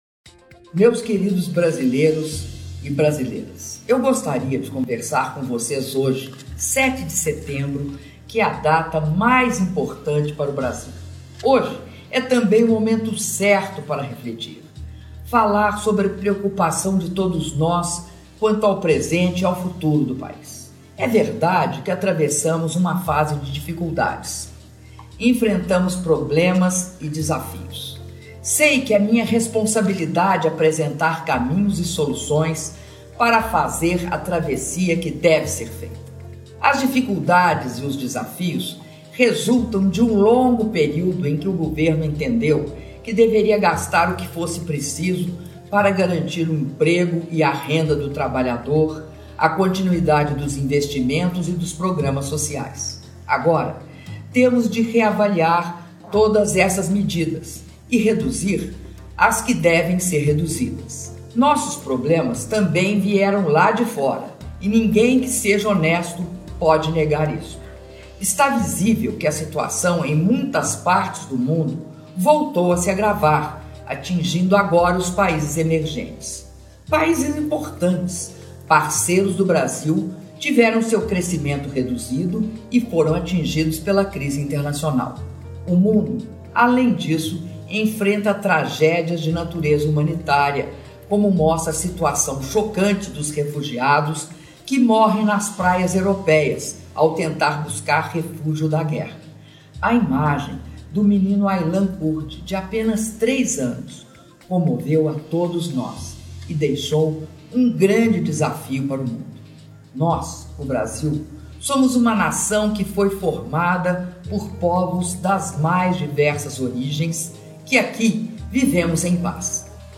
Áudio da mensagem da Presidenta da República, Dilma Rousseff, por ocasião do Dia da Independência